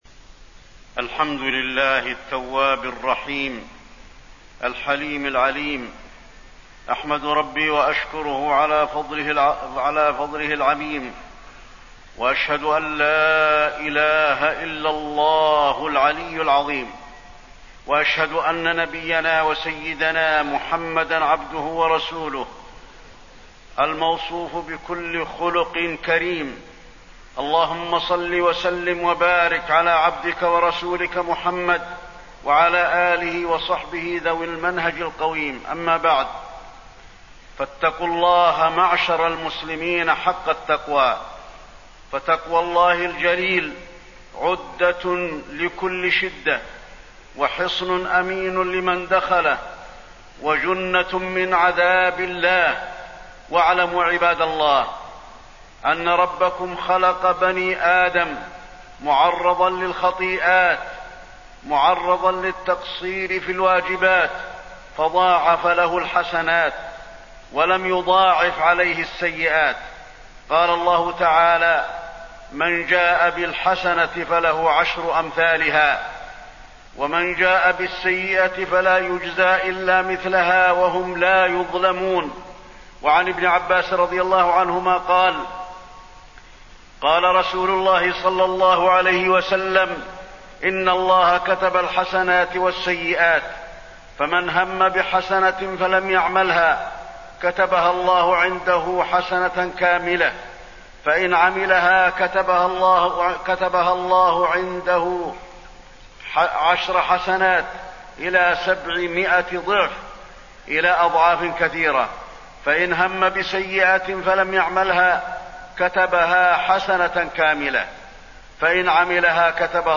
تاريخ النشر ٢٥ جمادى الأولى ١٤٣٢ هـ المكان: المسجد النبوي الشيخ: فضيلة الشيخ د. علي بن عبدالرحمن الحذيفي فضيلة الشيخ د. علي بن عبدالرحمن الحذيفي التوبة وفضائلها